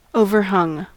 Ääntäminen
Ääntäminen Tuntematon aksentti: IPA : /oʊvərˈhʌŋ/ IPA : /ˈoʊvərˌhʌŋ/ Haettu sana löytyi näillä lähdekielillä: englanti Käännöksiä ei löytynyt valitulle kohdekielelle.